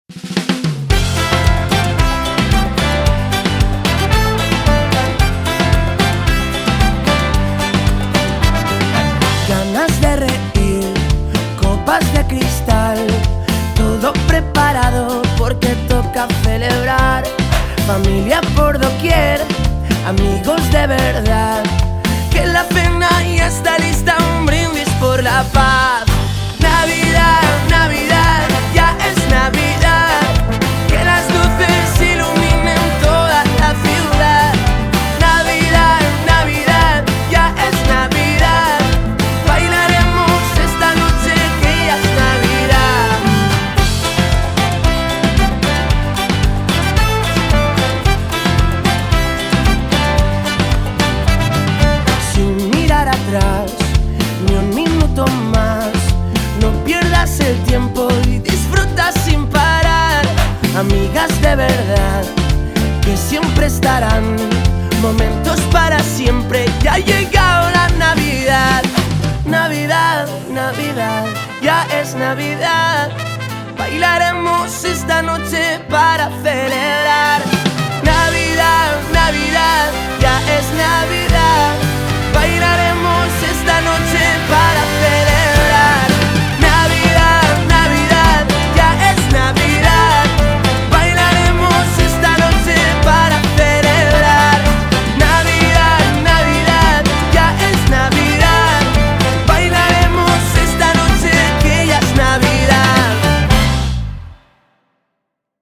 Cançó: